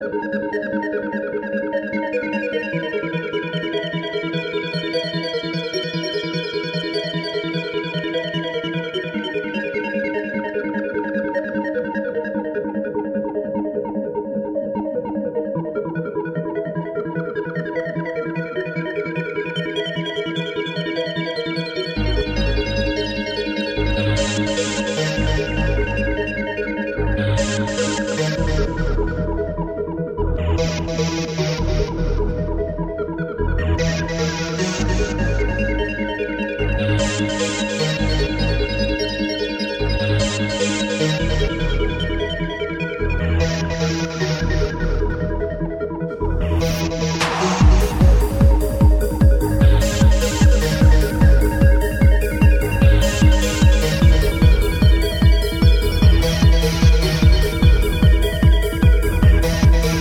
• Качество: 192, Stereo
без слов
нарастающие
90-е
космические
футуристические
Стиль: trance